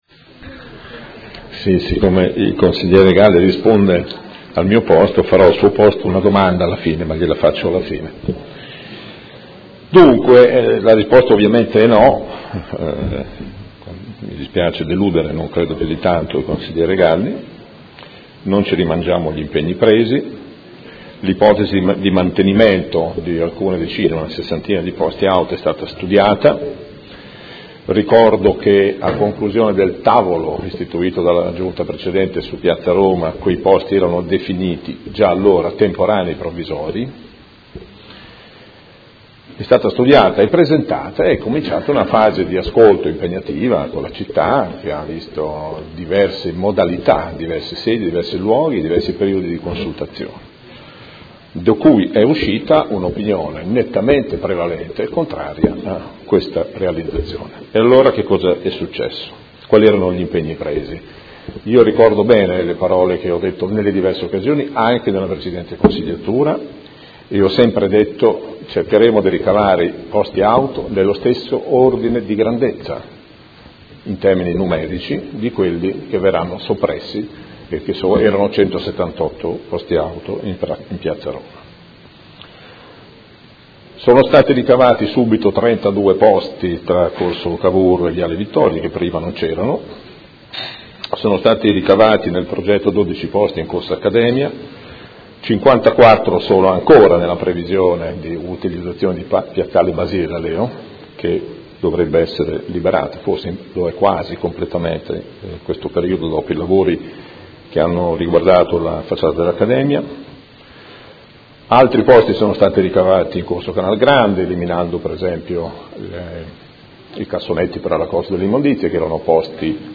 Gianpietro Cavazza — Sito Audio Consiglio Comunale
Che fine hanno fatto i 60 posti auto che si era impegnata a mantenere in Piazza Roma ad uso residenti? Risponde l'assessore